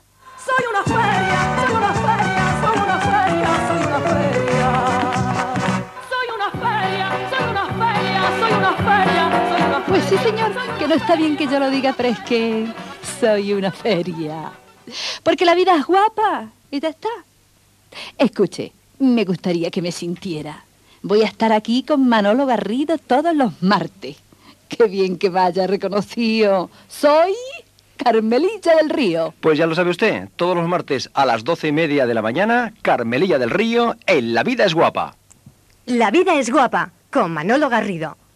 Promoció de la secció del programa protagonitzada pel personatge de Carmelilla del Río.